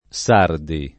[ S# rdi ]